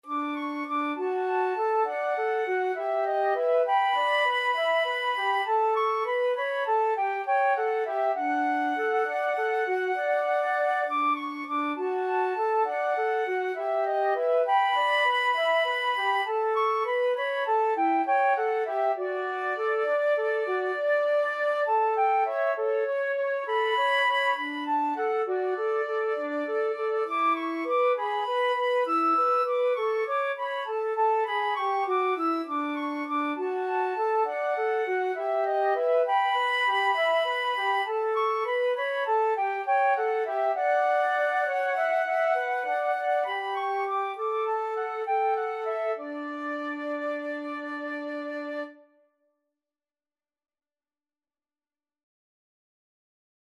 Free Sheet music for Flute Duet
Flute 1Flute 2
D major (Sounding Pitch) (View more D major Music for Flute Duet )
Moderato
9/8 (View more 9/8 Music)